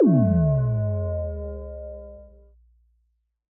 Minecraft Version Minecraft Version latest Latest Release | Latest Snapshot latest / assets / minecraft / sounds / block / beacon / deactivate.ogg Compare With Compare With Latest Release | Latest Snapshot
deactivate.ogg